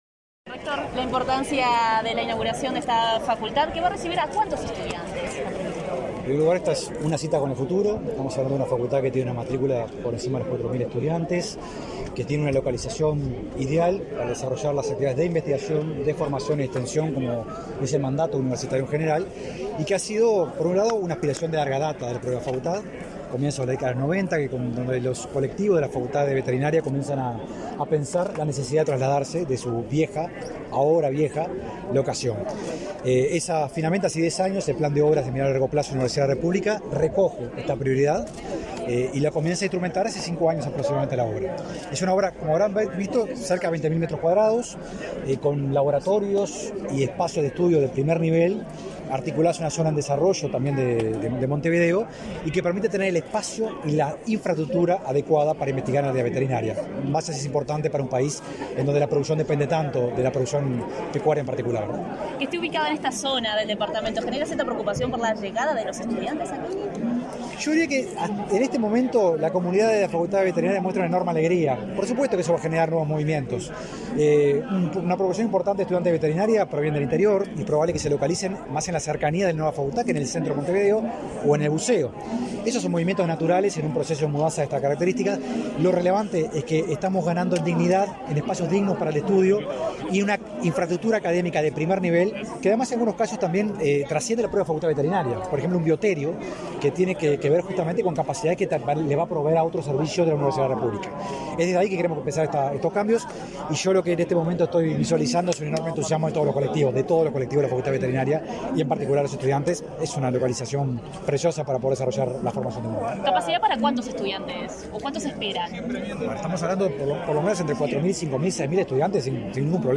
Declaraciones a la prensa del rector de la Universidad de la República, Rodrigo Arim
En el marco de la inauguración de la sede de la Facultad de Veterinaria, este 23 de noviembre, el rector de la Universidad, Rodrigo Arim, efectuó